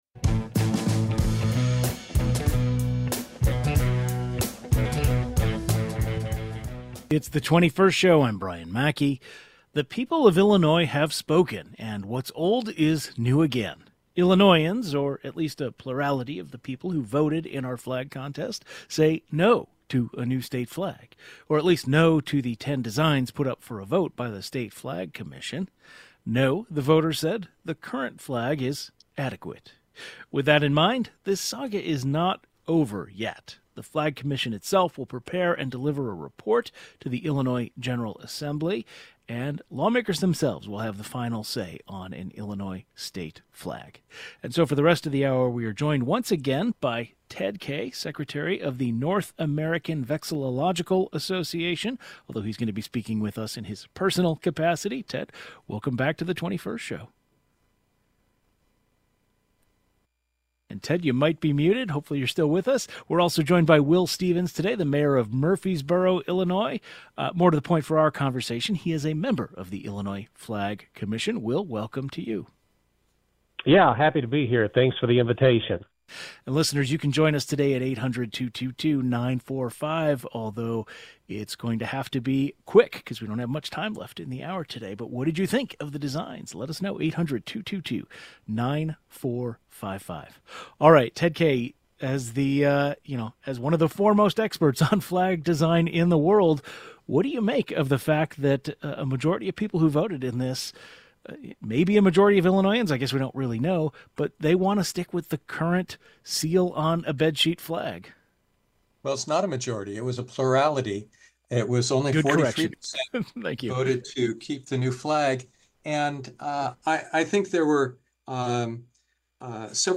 An expert on American flags and a member of the flag commission provide the latest updates.